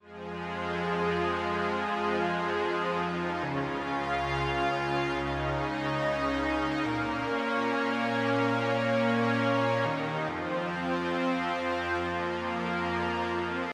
Category: Ambient Ringtones Tags